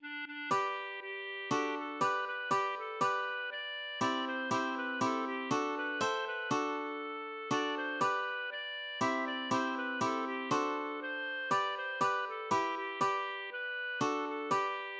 LilyPond 🏰" } myMusic = { << \chords { \germanChords \set chordChanges=##t \set Staff.midiInstrument="acoustic guitar (nylon)" s4
g2 d4 g4\fermata \fine } \relative c' { \time 5/4 \key g \major \tempo 4=120 \partial 4 \set Staff.midiInstrument="clarinet" d8 d8